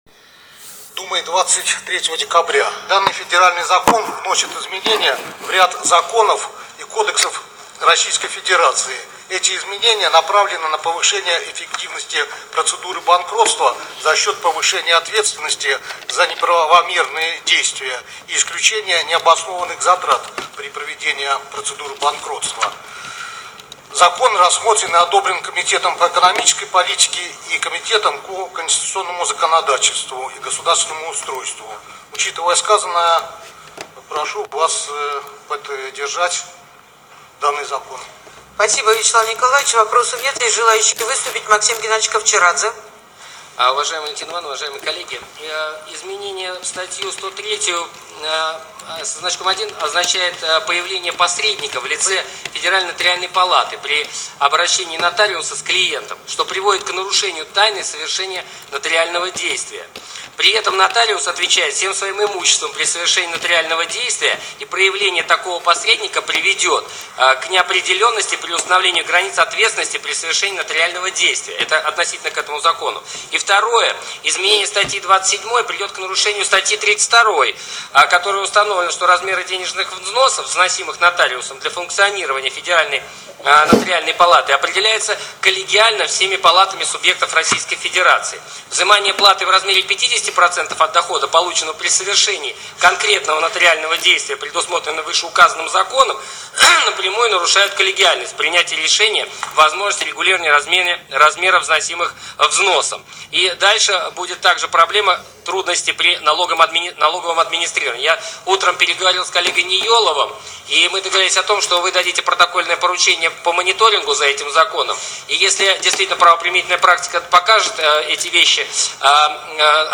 Часть аудиозаписи заседания Совета Федерации 25 декабря 2015 года, про наш законопроект первые 4 минуты.